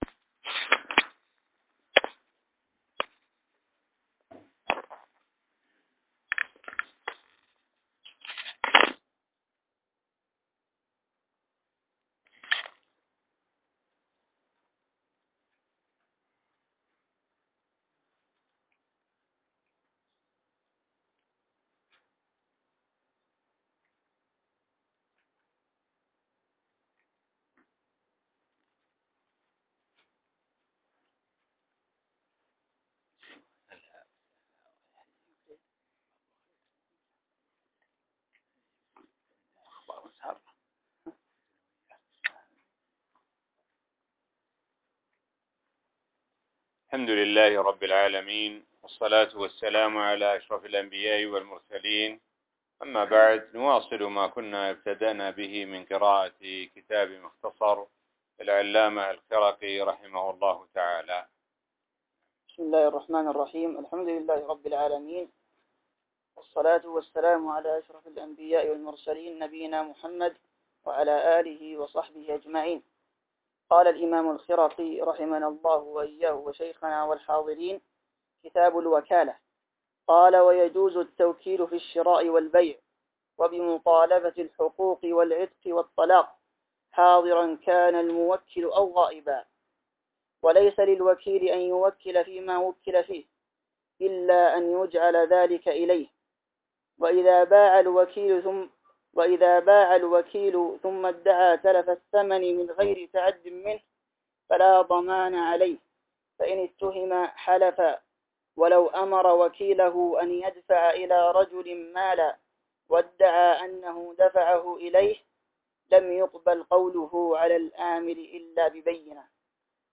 الموقع الرسمي لفضيلة الشيخ الدكتور سعد بن ناصر الشثرى | الدرس--29 الوكالة - الإقرار